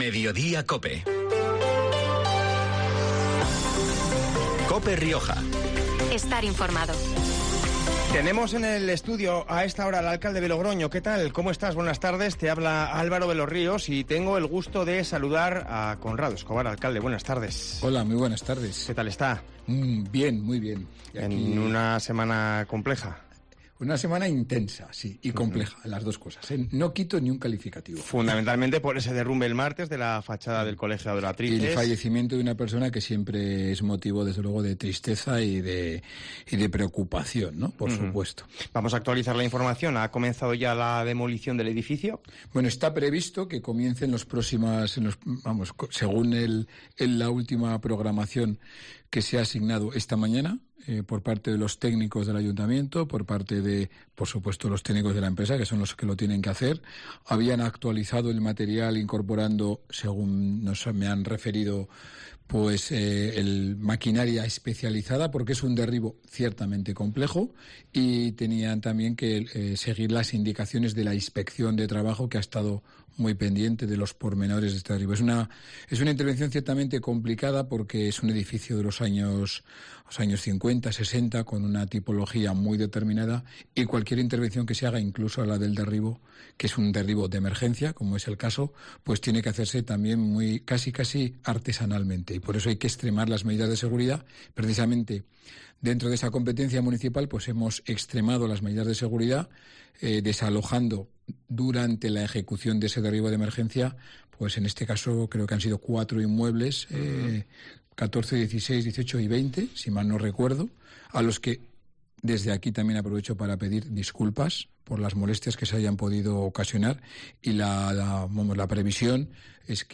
Conrado Escobar, alcalde de Logroño, en los micrófonos de COPE